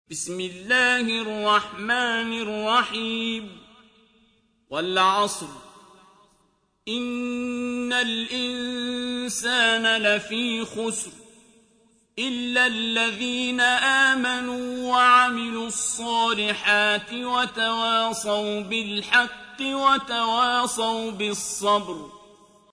سورة العصر | القارئ عبدالباسط عبدالصمد